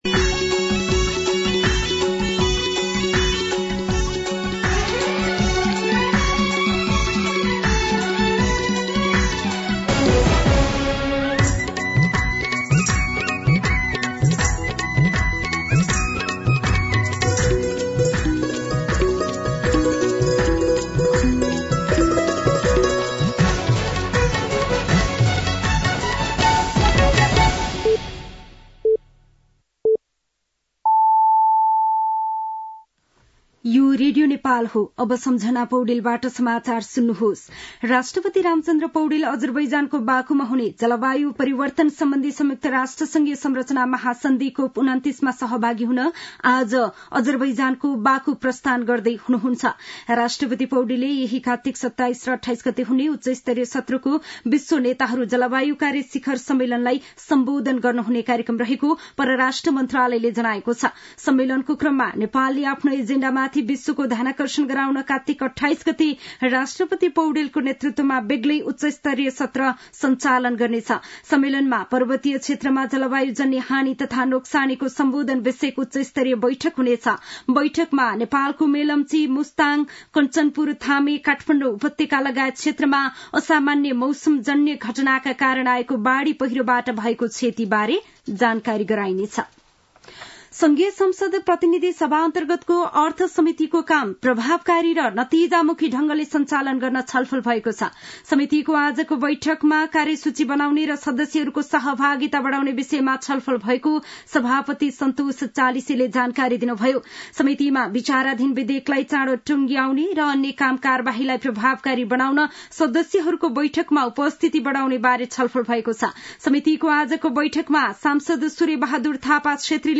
An online outlet of Nepal's national radio broadcaster
दिउँसो ४ बजेको नेपाली समाचार : २६ कार्तिक , २०८१
4-pm-news-1.mp3